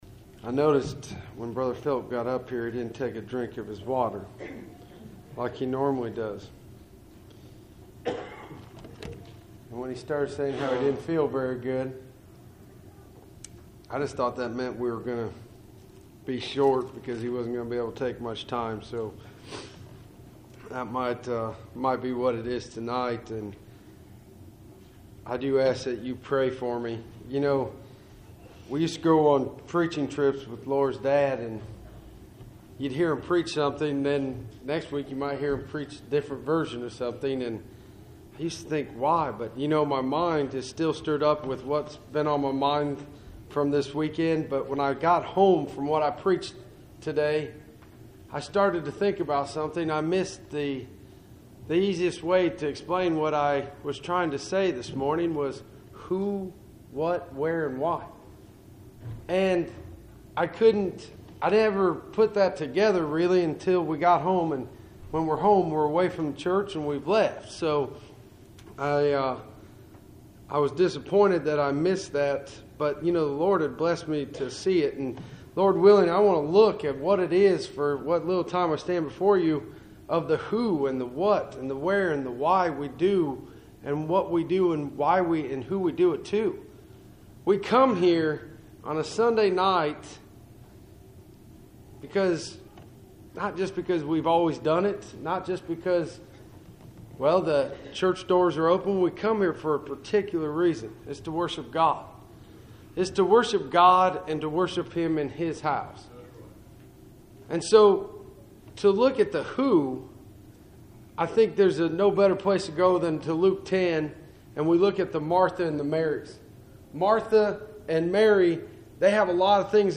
Cool Springs PBC Sunday Evening %todo_render% « II Timothy 4:9-15